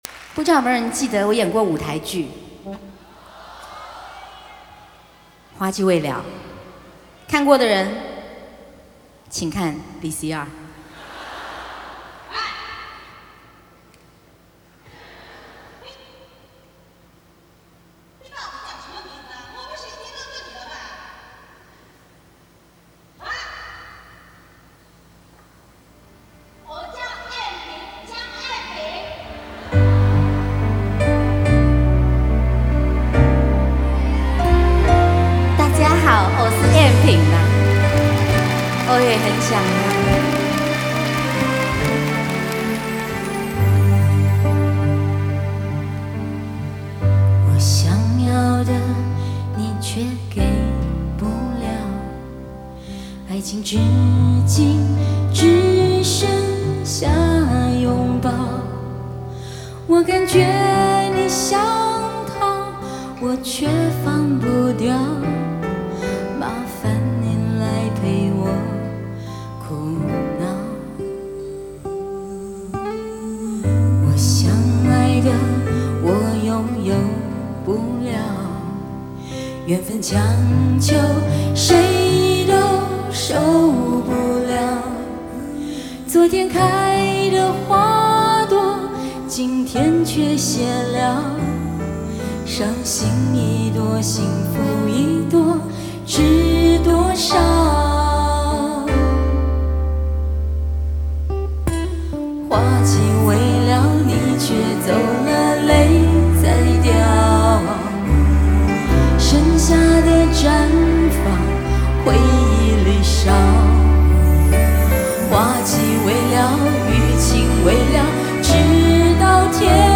电音 收藏 下载